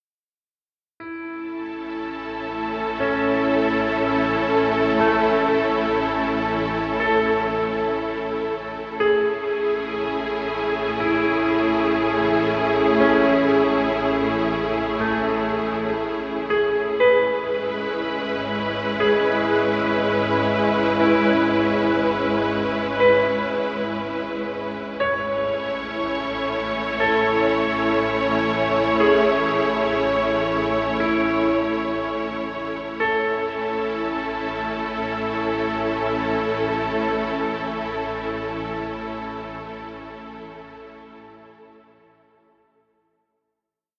Relax music.